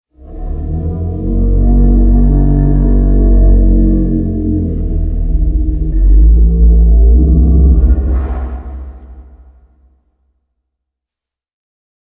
moo.wav